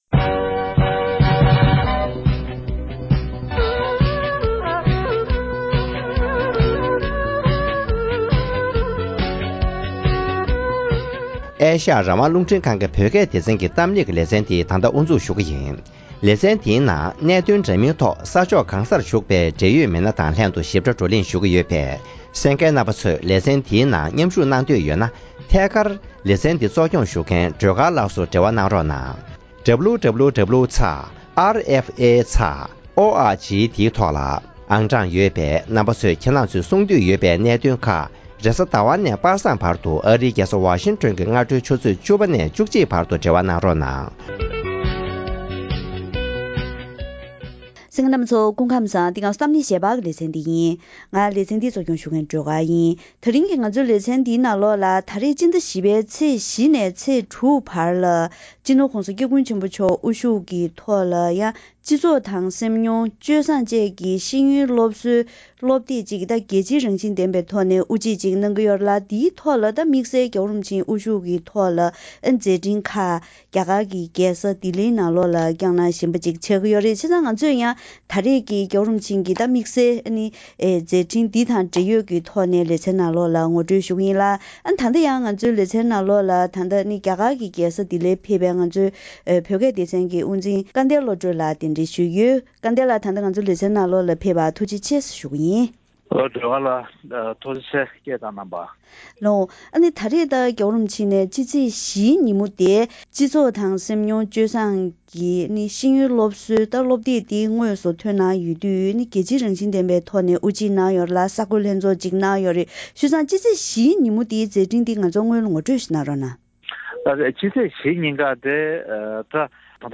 ཉེ་ཆར་༧གོང་ས་༧སྐྱབས་མགོན་ཆེན་པོ་མཆོག་དབུ་བཞུགས་ཐོག་དབུ་འབྱེད་གནང་བའི་སྰི་ཤེས་ཡོན་སློབ་གསོའི་ཁྱད་ཆོས་དང་དམིགས་ཚད་སོགས་ཀྱི་ཐད་གླེང་མོལ་ཞུས་པ།